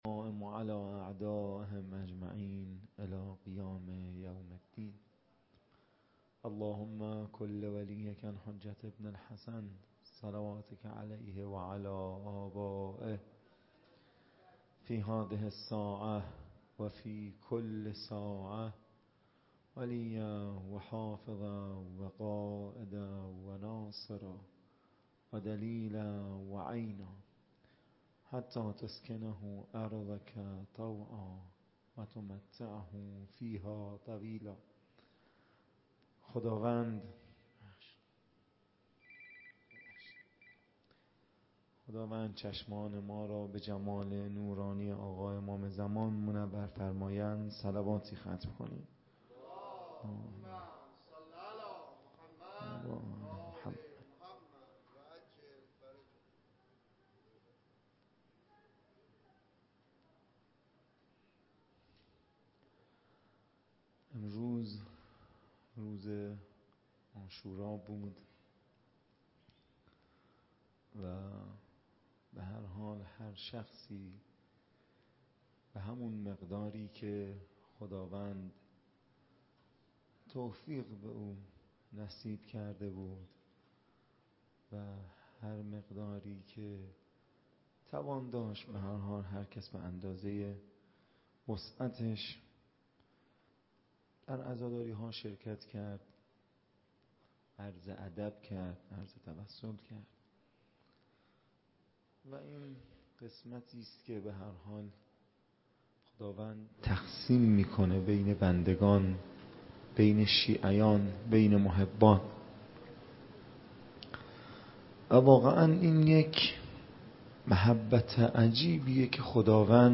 سخنرانی - روضه